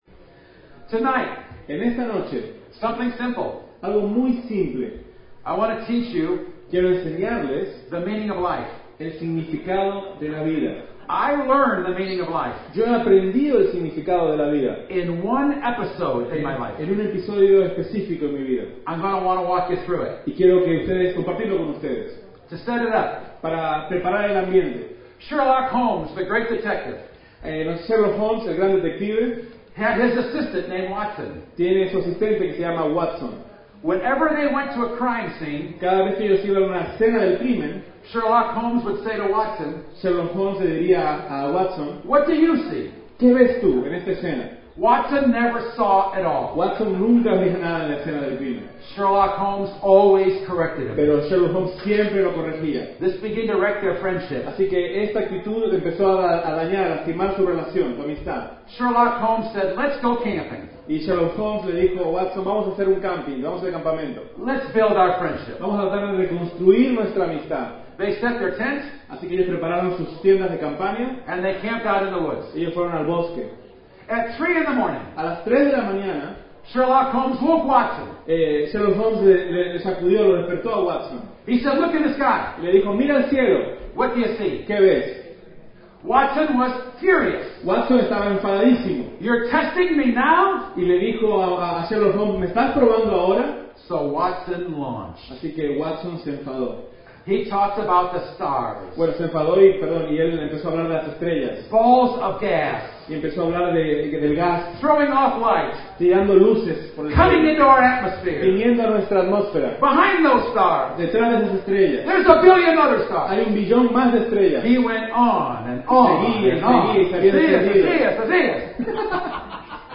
This audio is from the Wednesday evening service of the EuNC Leadership Conference 2014.